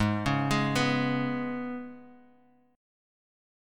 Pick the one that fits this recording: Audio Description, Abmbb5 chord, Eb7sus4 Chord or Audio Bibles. Abmbb5 chord